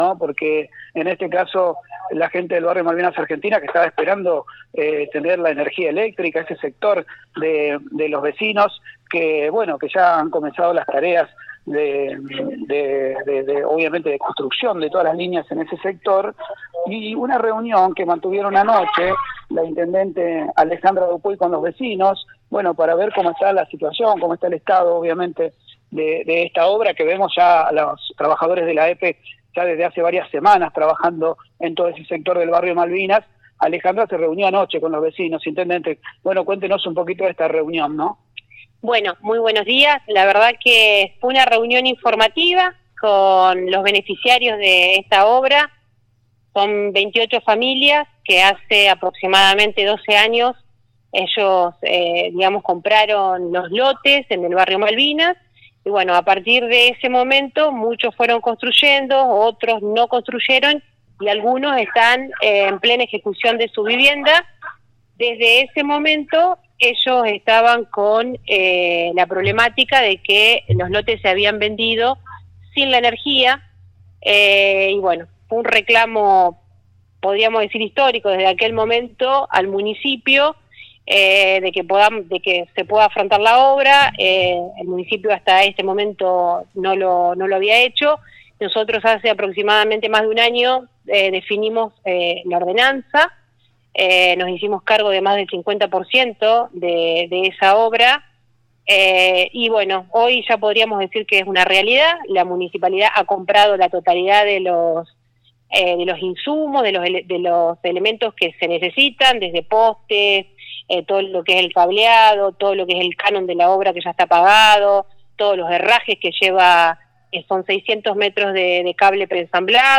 Escucha las declaraciones de la Intendente Alejandra Dupouy.